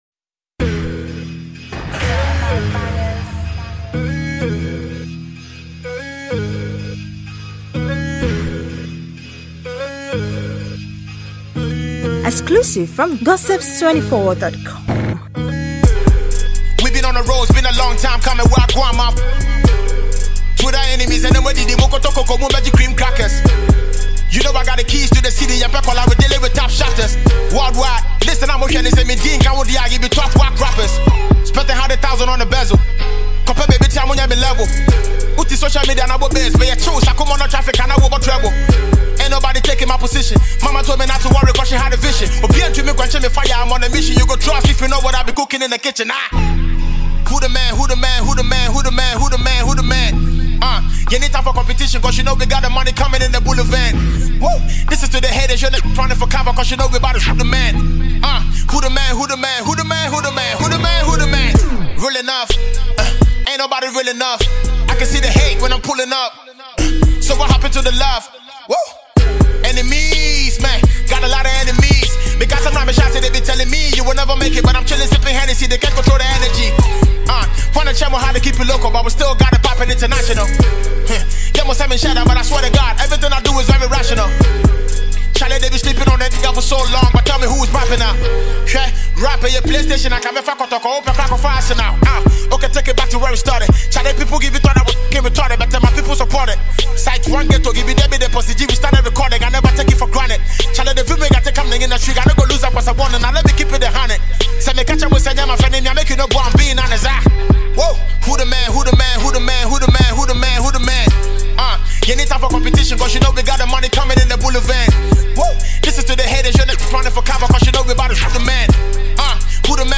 hip hop tune